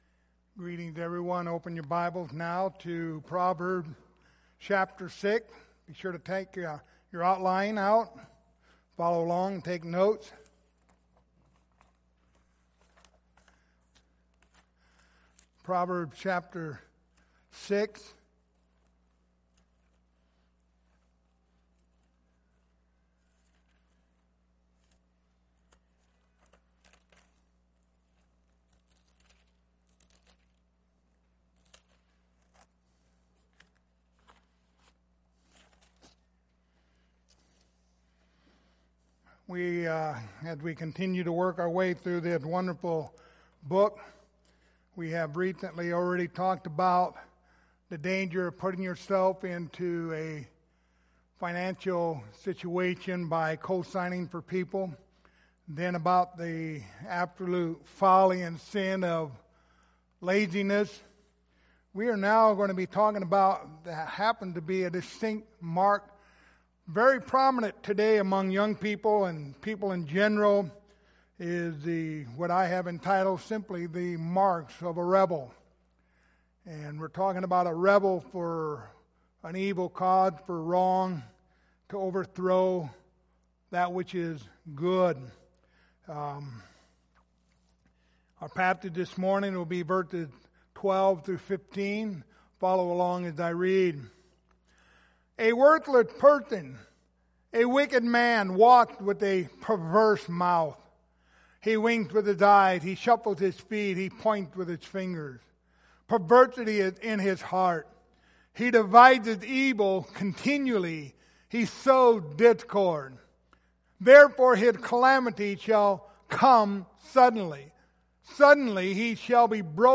Passage: Proverbs 6:12-15 Service Type: Sunday Evening Topics